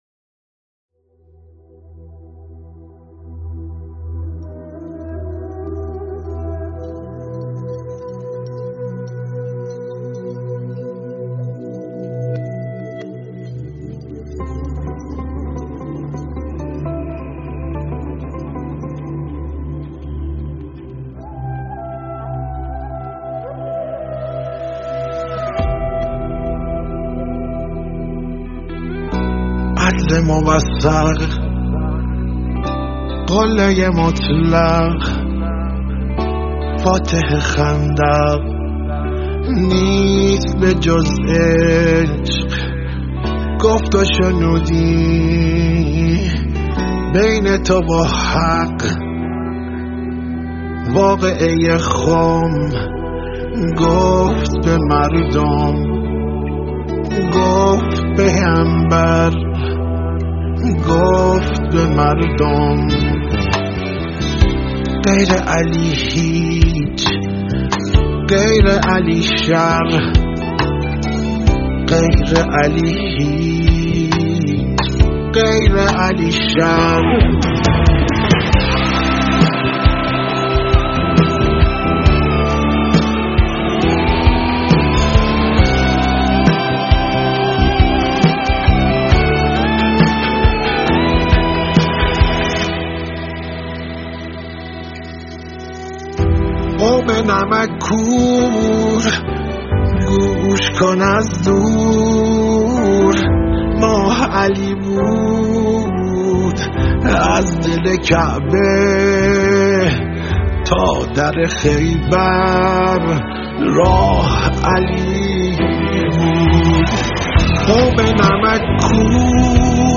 گیتار الکتریک
گیتار آکوستیک
پن فلوت و دودوک